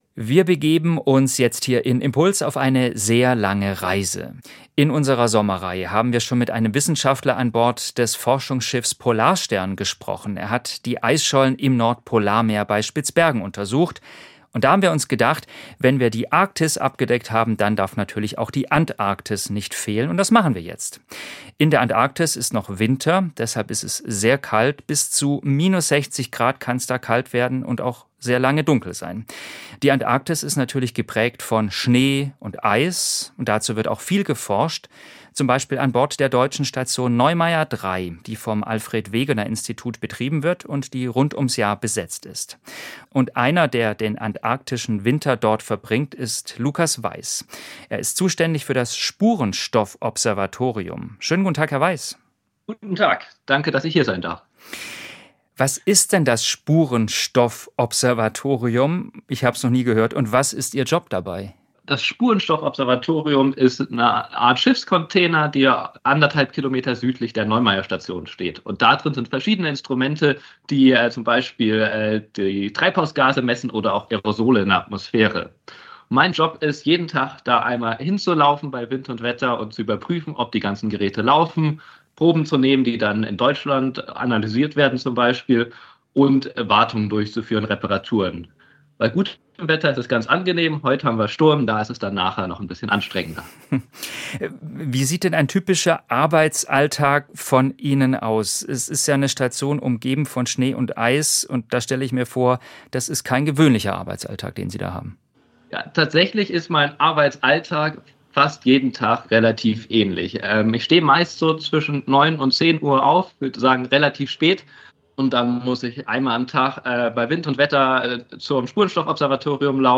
im Gespräch